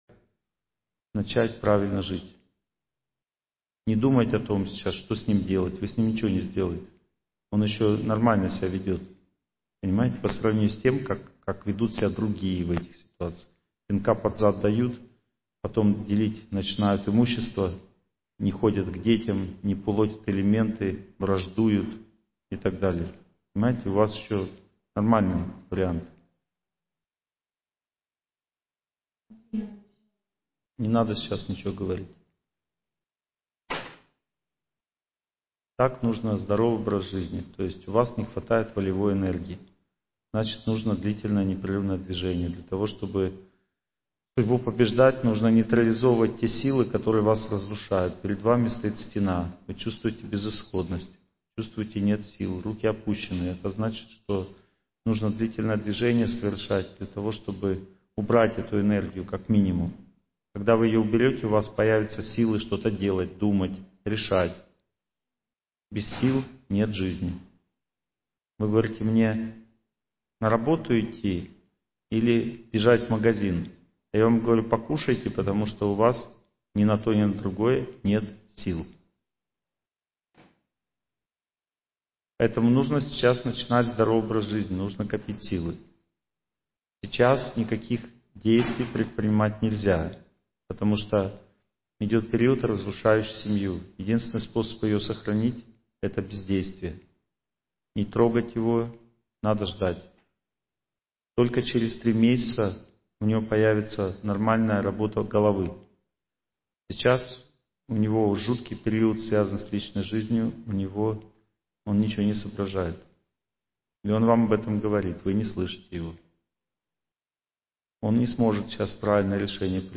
Победа над судьбой. Лекция 2